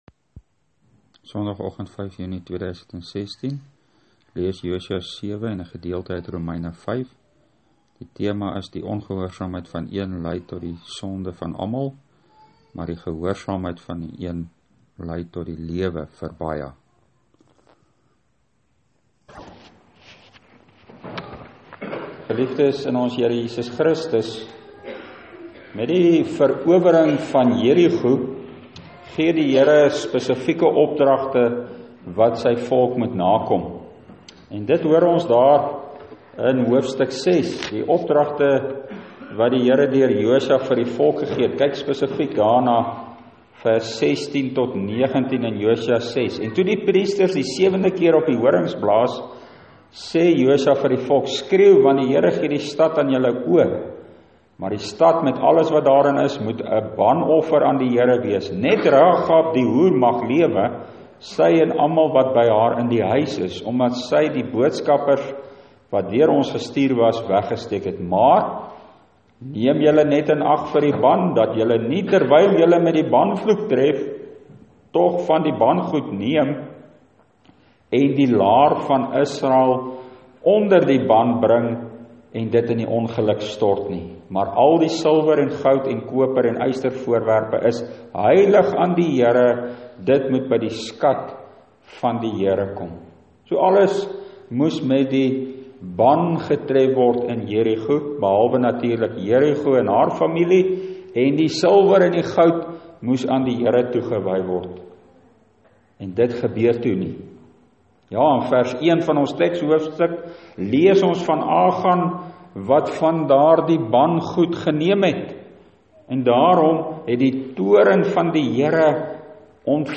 Preekopname (GK Carletonville, 2016-06-05):